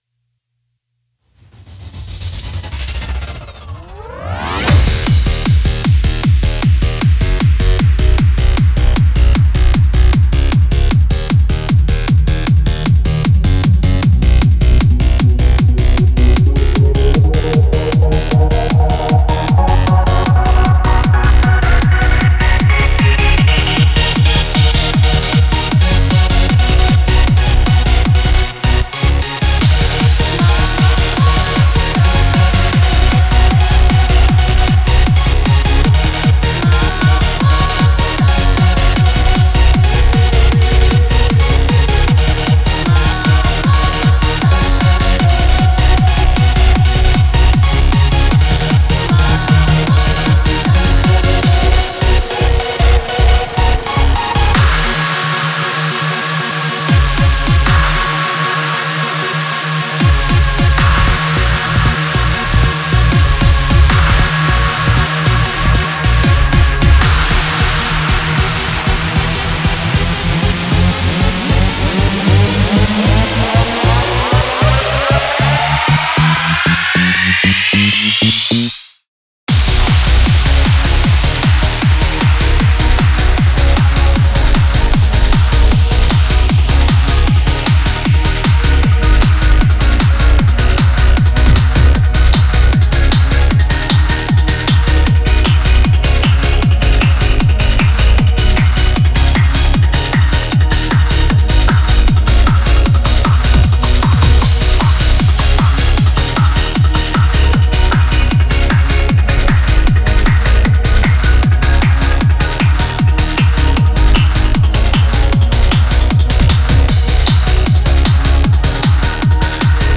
コーラス部分はそのまま残しています。